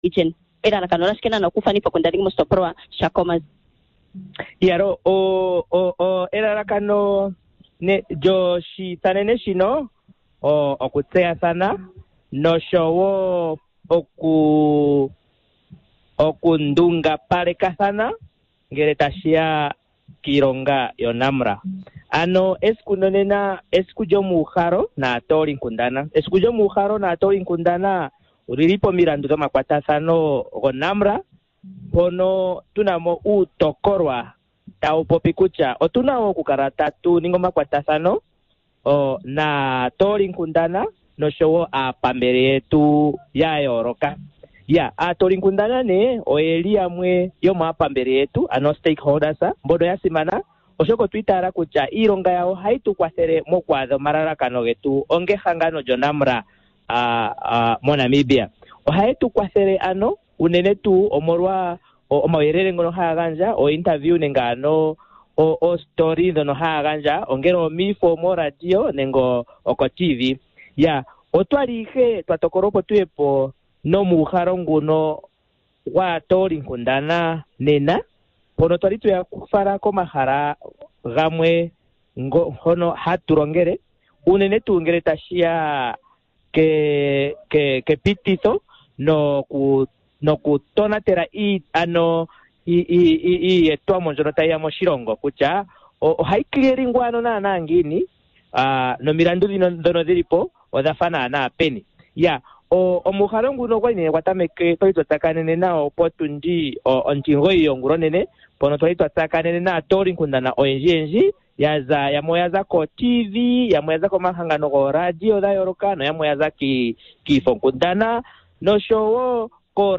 24 Mar Interview with Public Relations office of NAMRA